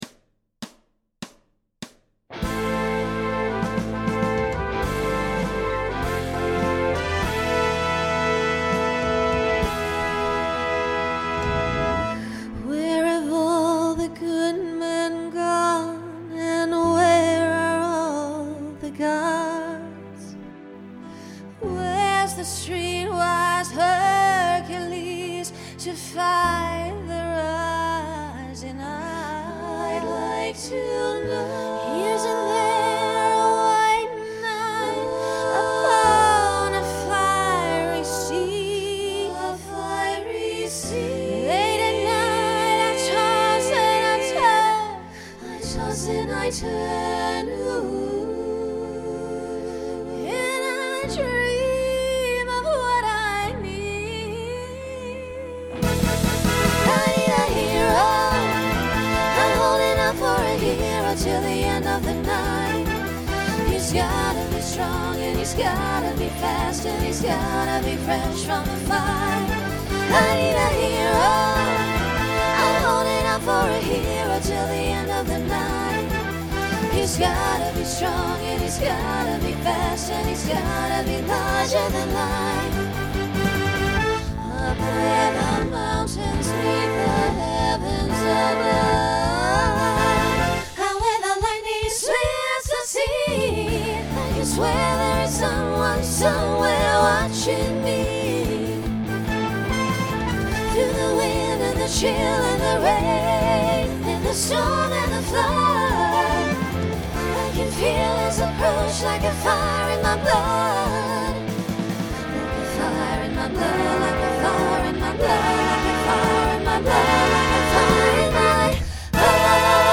Genre Rock
Transition Voicing Mixed